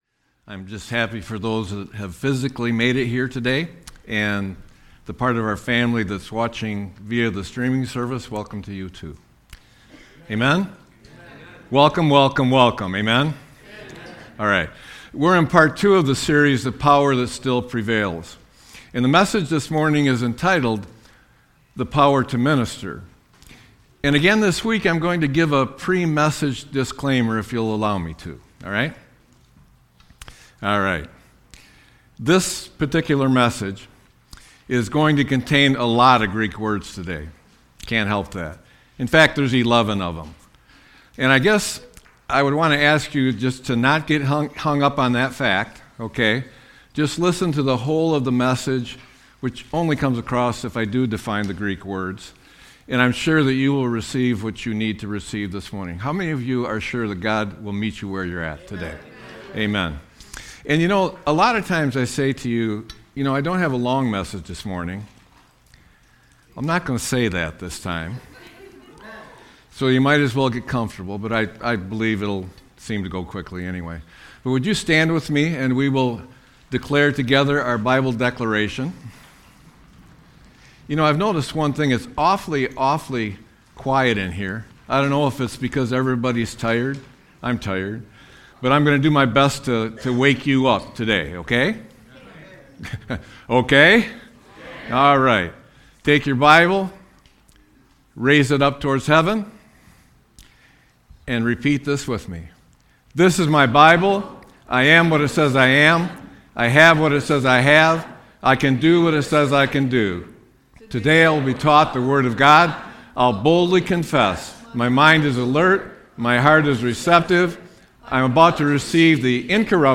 Sermon-2-01-26.mp3